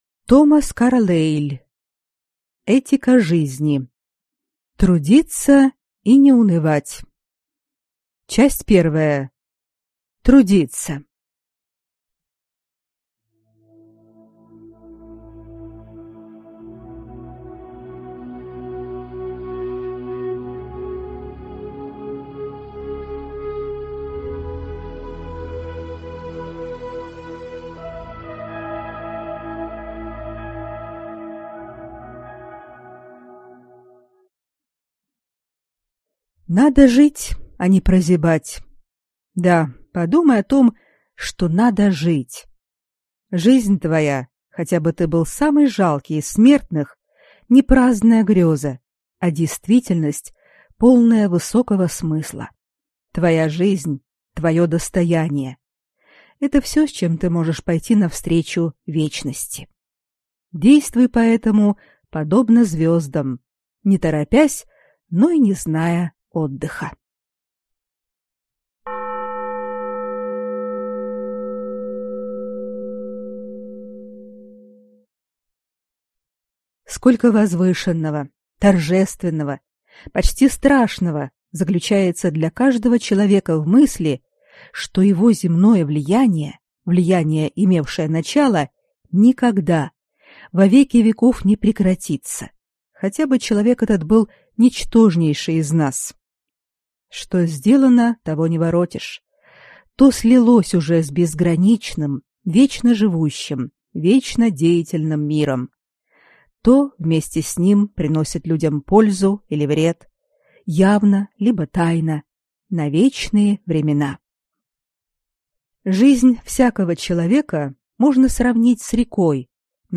Аудиокнига Этика жизни. Трудиться и не унывать!